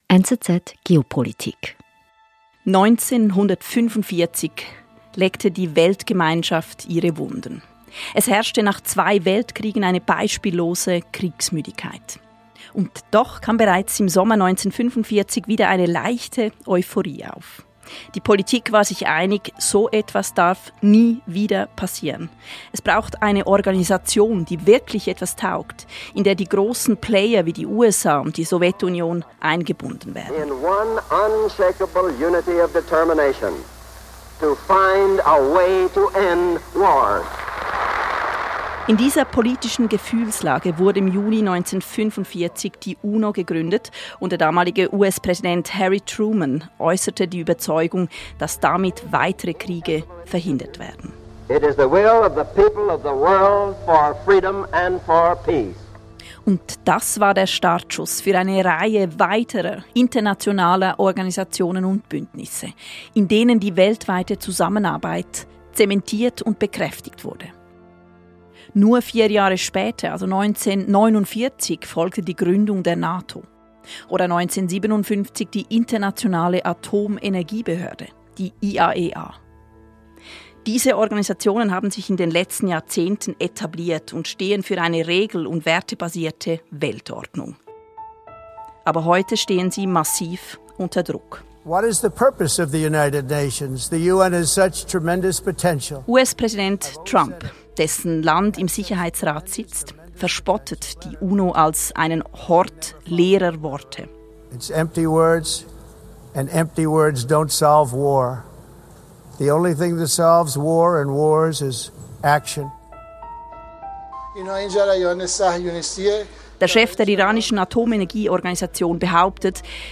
die Politikwissenschaftlerin